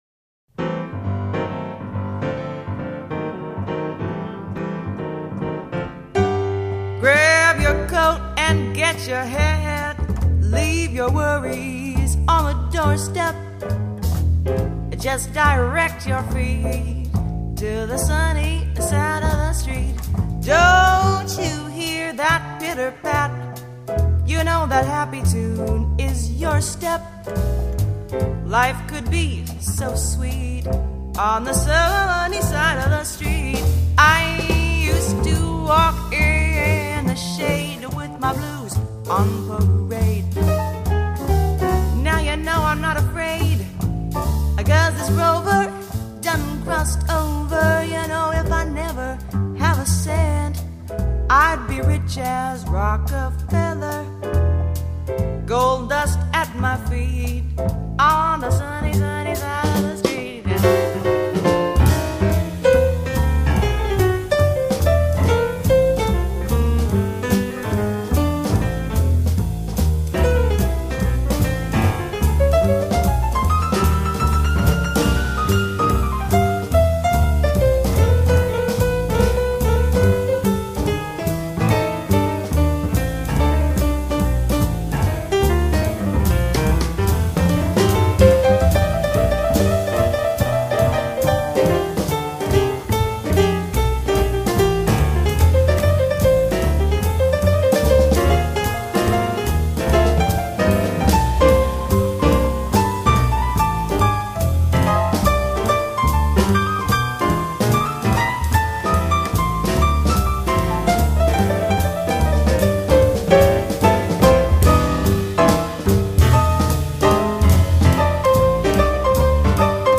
音乐类型：爵士乐